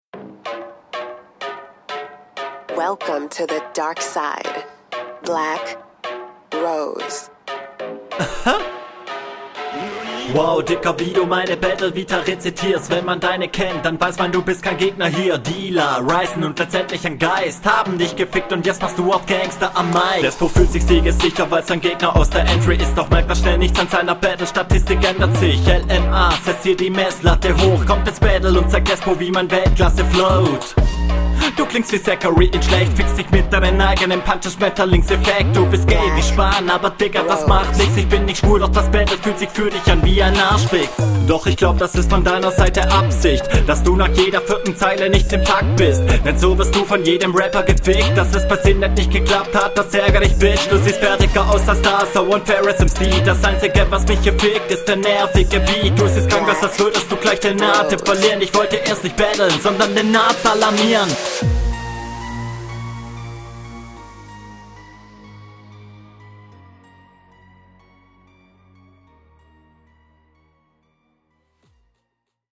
find deine stimme cooler auf dem beat. außerdem ist dein mix diesmal besser und dadurch …